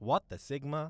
what the sigma.wav